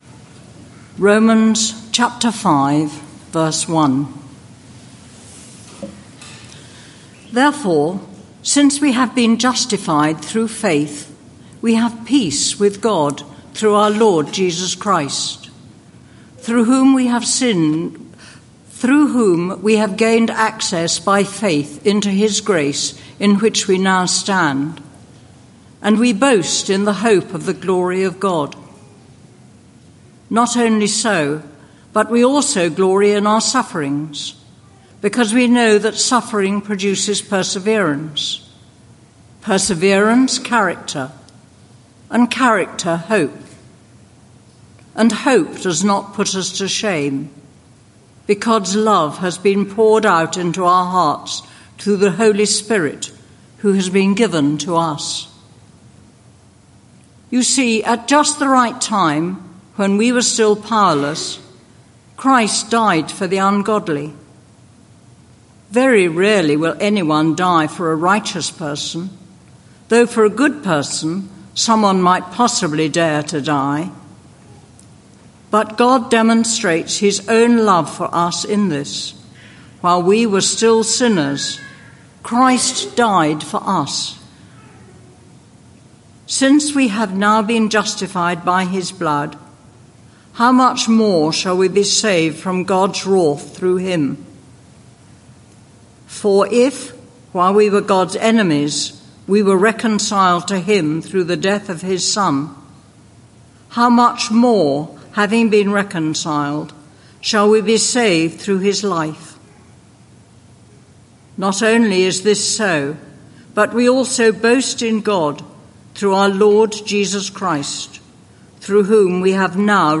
This sermon is part of a series: